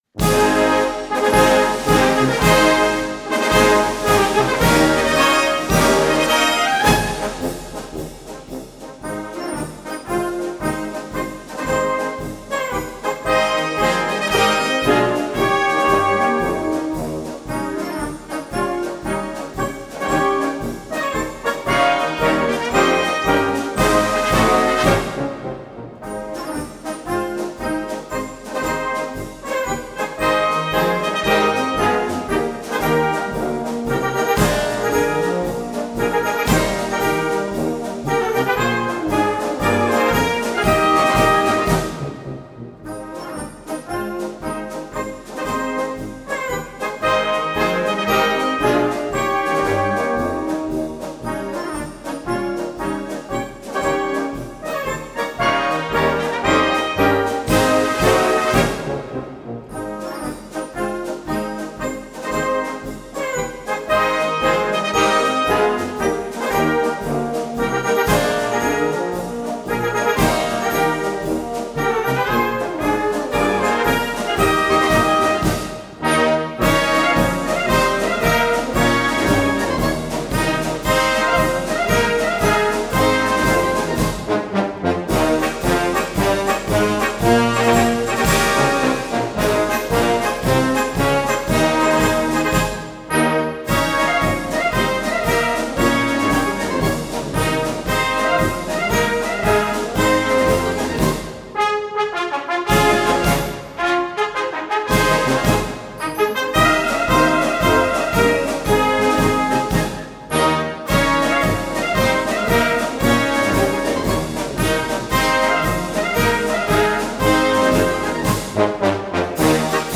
Gattung: Konzertmarsch
Besetzung: Blasorchester
Ein Konzertmarsch der Extraklasse